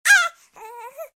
babywhimper2.ogg